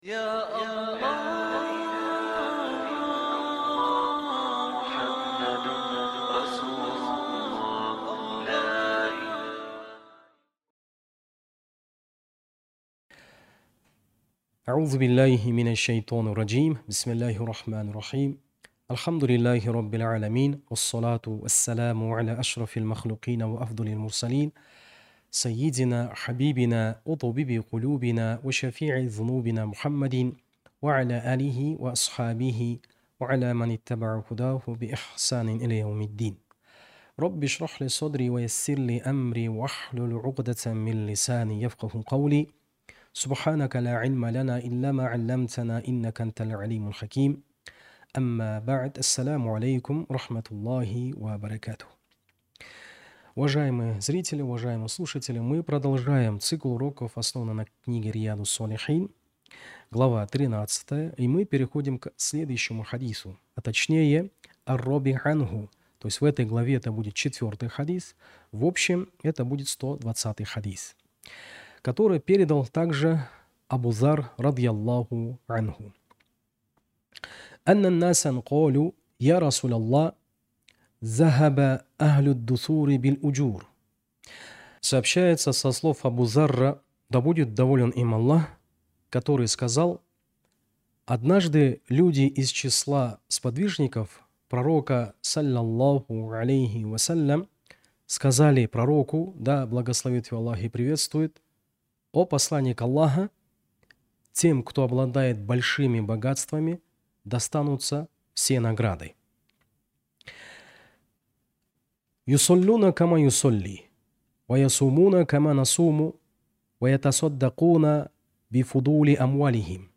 Циклы уроков